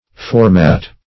Format \For`mat"\ (f[-o]r`m[.a]" or f[-o]r`m[aum]t"), n. [F. or